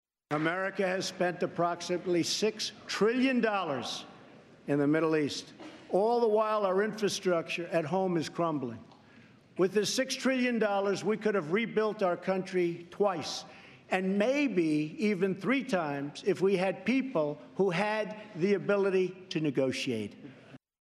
Jawabin Shugaban Amurka Trump A Gaban 'Yan Majalisun Tarayyar Kasar